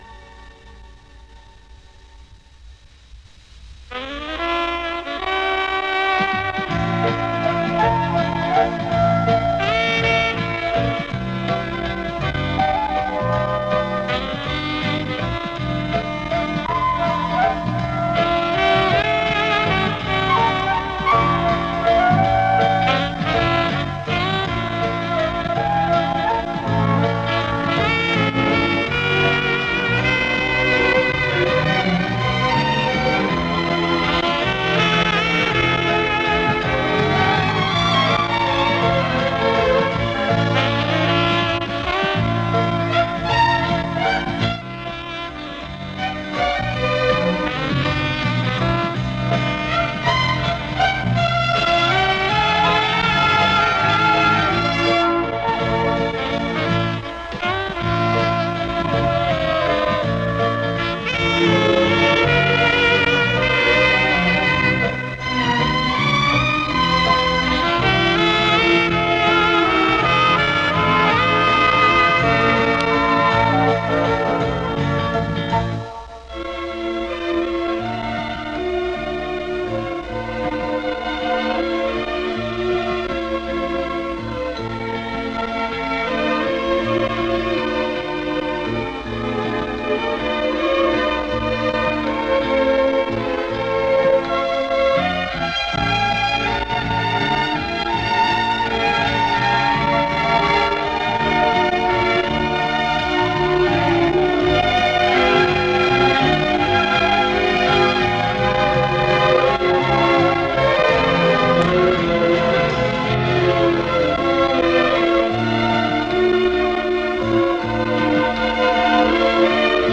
Несколько старых записей с приёмника. Качество плоховатое.